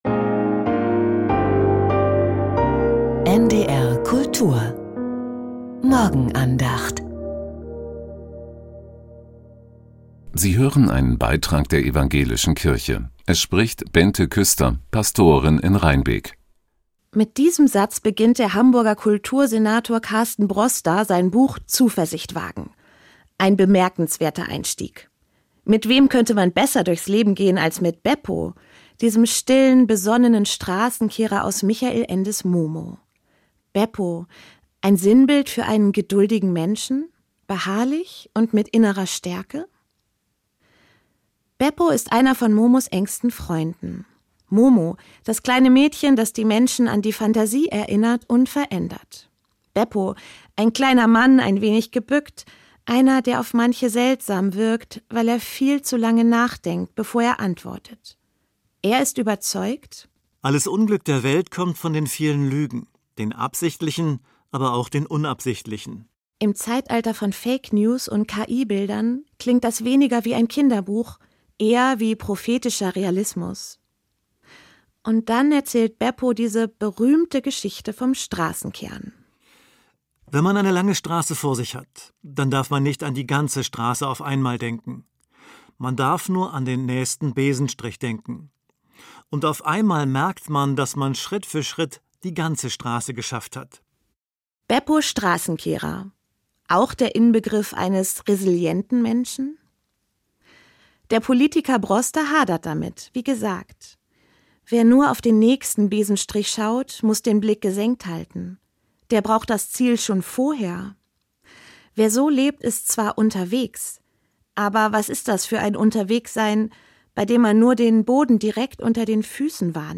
Beppo Straßenkehrer: Ein Meister der Resilienz ~ Die Morgenandacht bei NDR Kultur Podcast